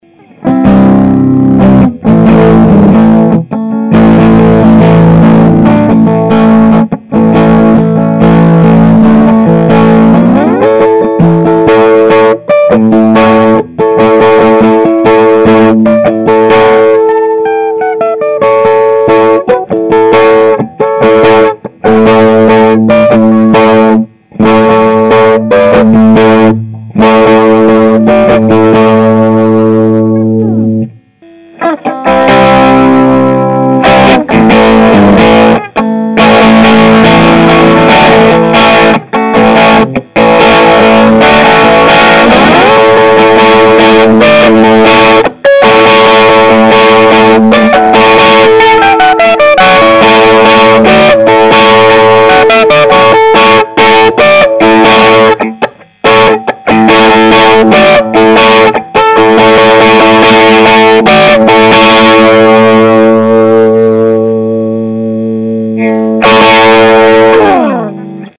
Gli esempi audio sono stati registrati usando la mia Red Special (quella della sez. Chitarra& Ampli) e un amplificatore valvolare VOX AC 30 Top Boost (quello dietro).
NB: I file contengo i prezzi eseguiti 2 volte di fila; PRIMA SENZA l'uso del Treble e POI CON il Booster Cornish-like TB-83.
Le registrazioni sono state effettuate microfonando l'amplificatore e NESSUN ALTRO EFFETTO è stato utilizzato.
Gilmour Style:        Slow solo                 1/2            Neck